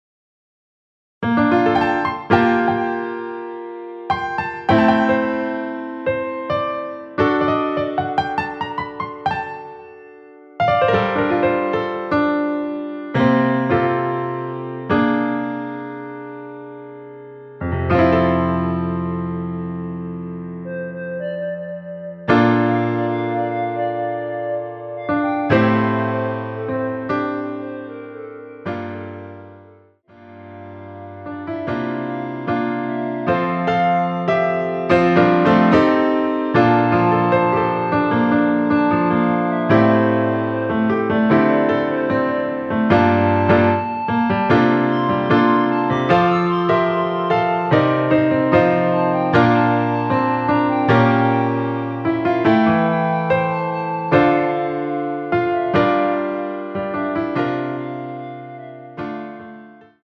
Piano RMX
원키에서(+5)올린 멜로디 포함된 MR 입니다.(미리듣기 참조)
앞부분30초, 뒷부분30초씩 편집해서 올려 드리고 있습니다.